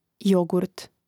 jògurt jogurt